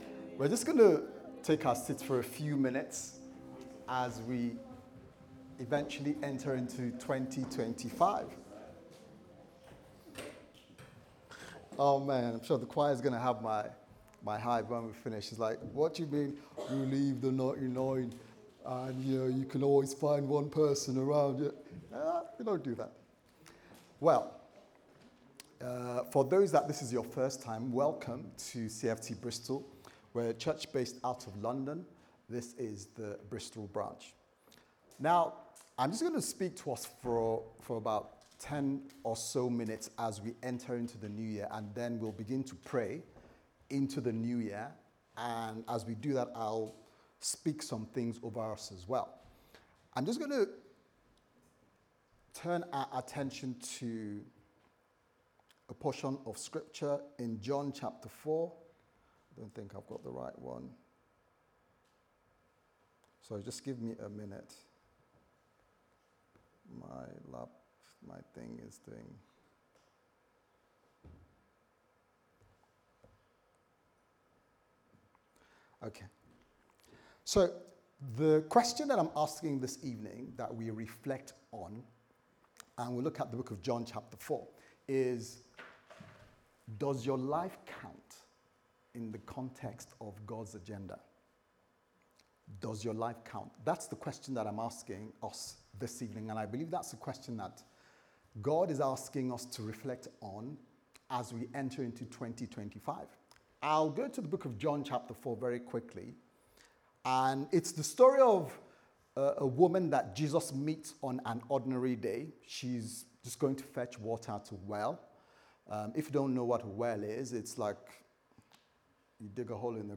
Sunday Service Sermon « Jesus Asked Who Do You Say That I am